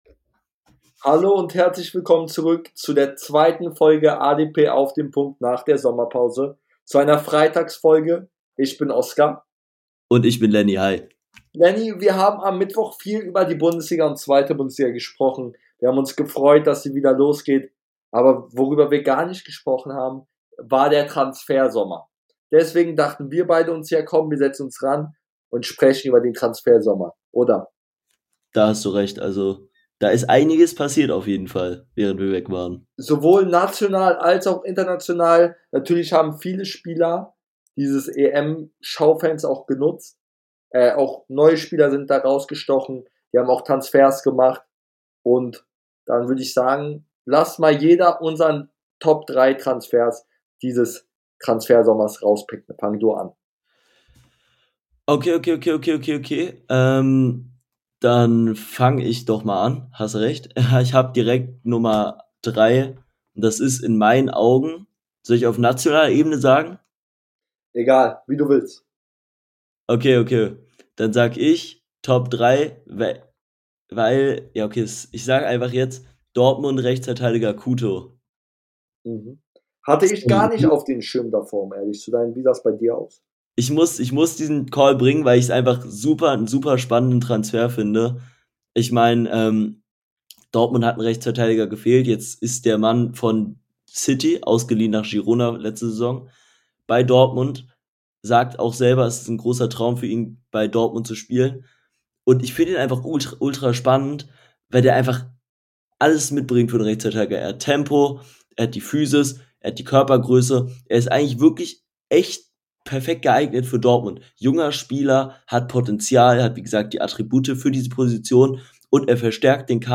In der heutigen Freitags Folge sprechen die beiden Hosts über den Transfersommer , geben ihre Top 5 Transfers , sprechen über Brighton Hove Albion und vieles mehr